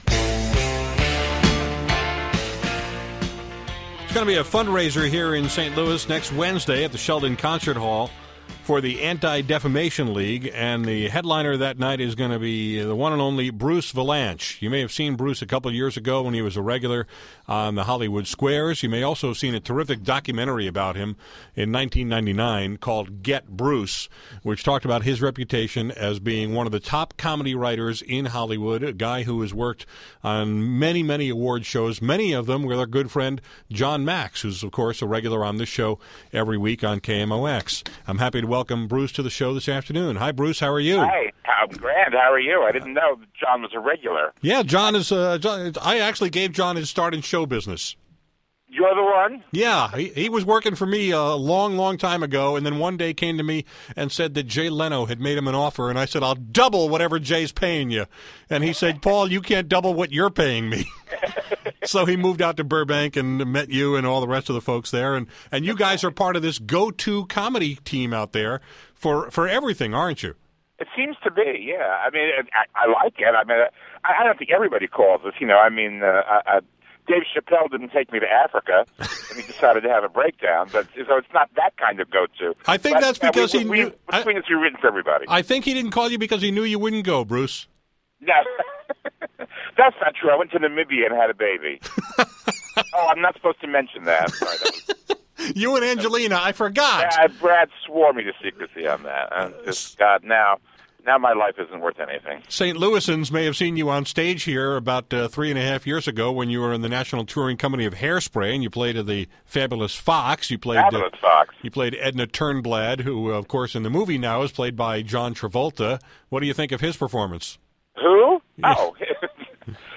Today on my show, I talked with comedy writer Bruce Vilanch, who you may have seen at the Fox Theater in 2004 as Edna Turnblad in “Hairspray,” or as one of the celebs on “Hollywood Squares,” or as the subject of the documentary “Get Bruce!”